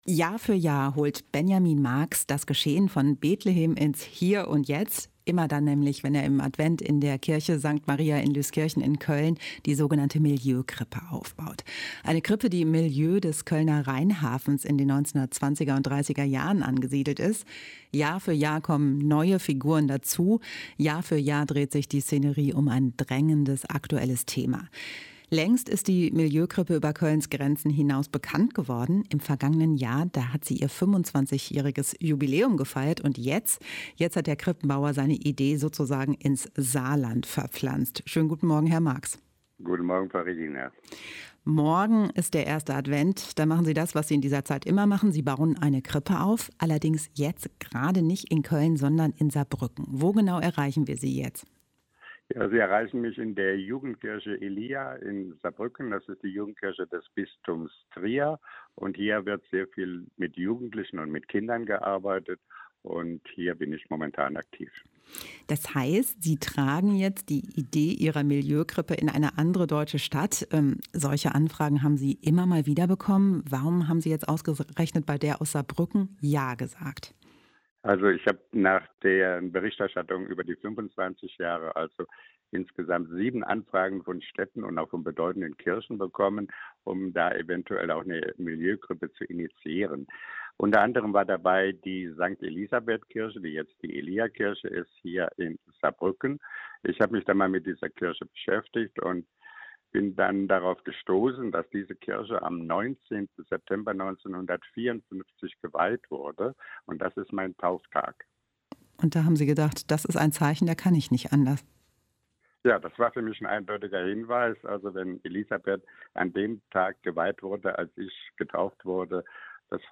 Im Interview erklärt er, wie es dazu kam.